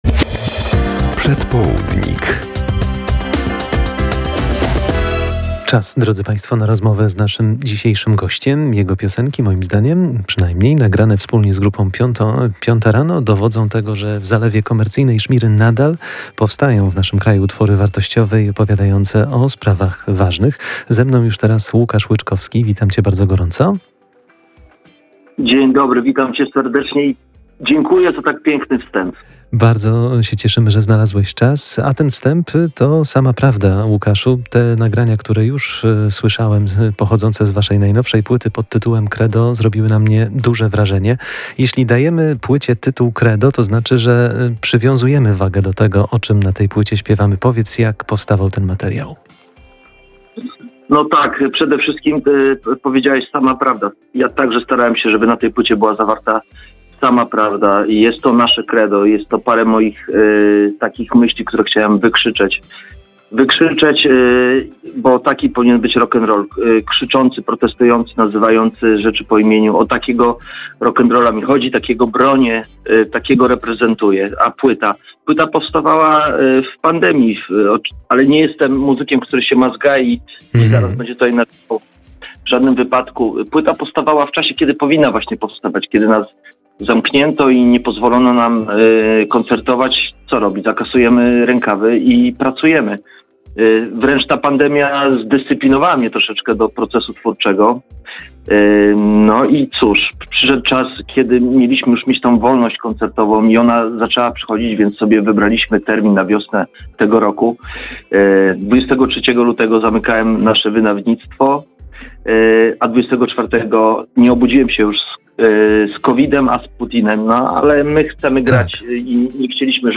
Prawda i pasja ubrane w dźwięki [POSŁUCHAJ ROZMOWY]